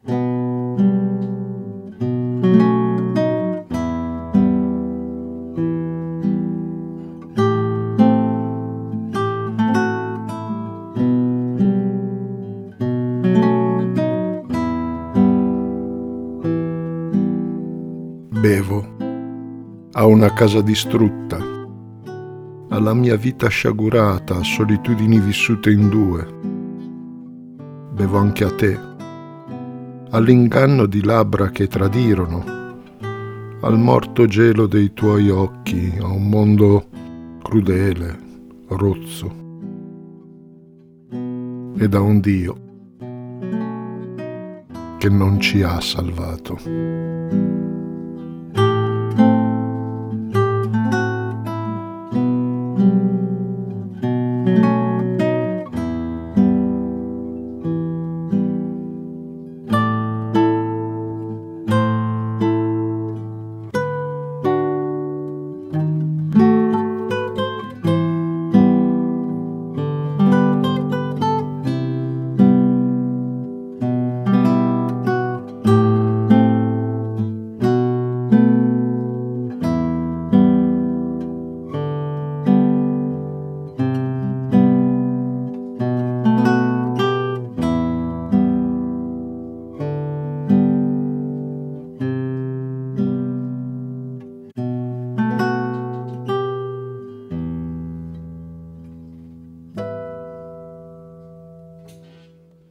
LETTURE ANIMATE   imgSpaziatrice